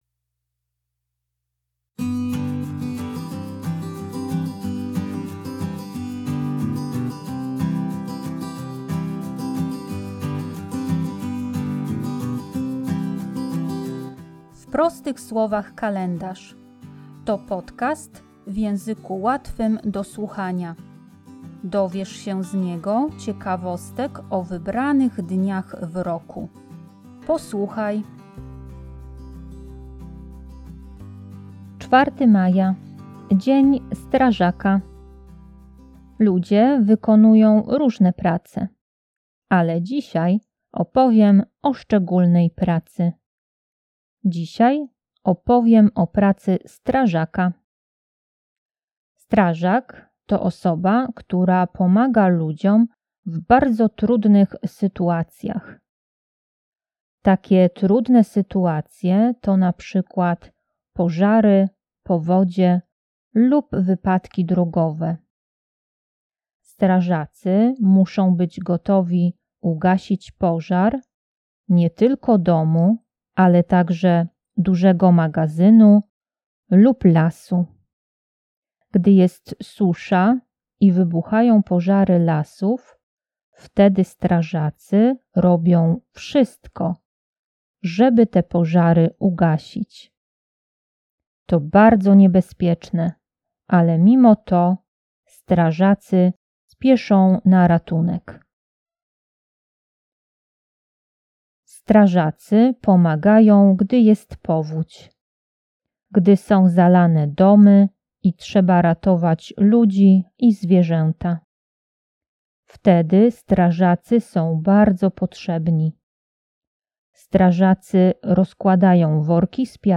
Posłuchajcie!W podcaście usłyszycie głośne dźwięki wozu strażackiego.